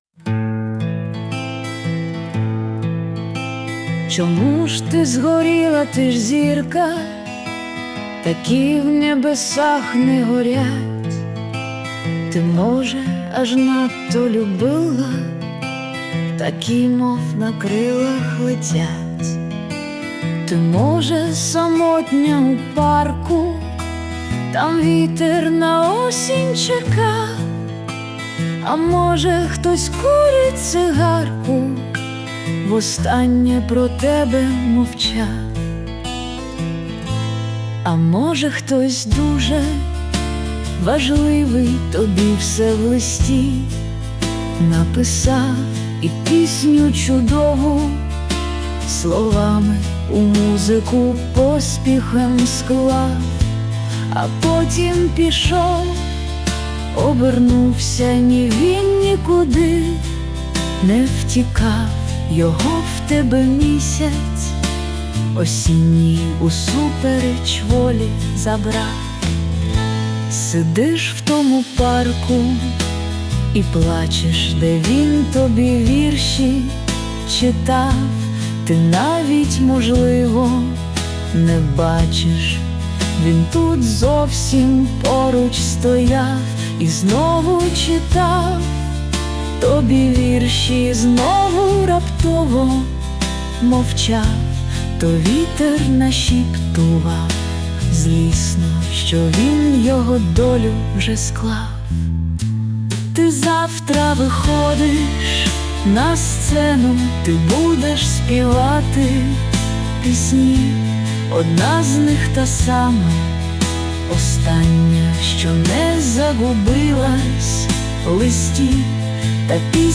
Музичне прочитання з допомогою ШІ
Дуже тепла пісня, хоча і з присмаком смутку. 16 12 give_rose